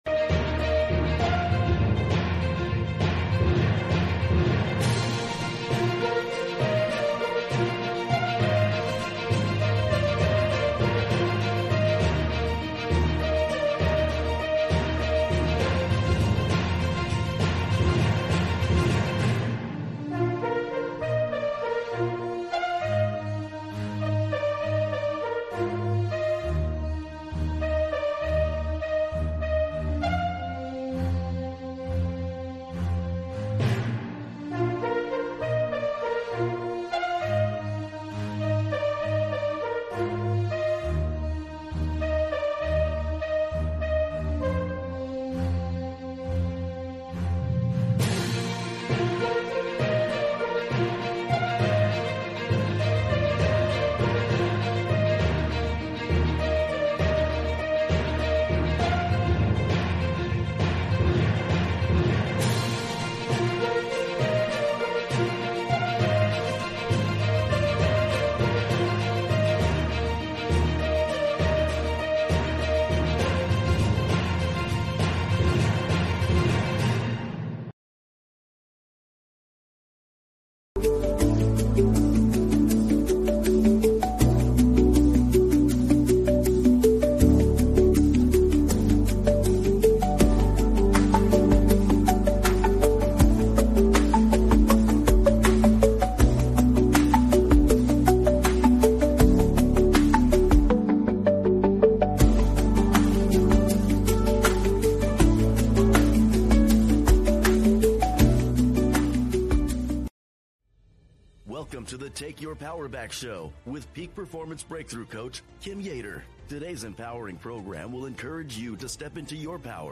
The show features discussions with freedom-loving Americans who are making a positive impact.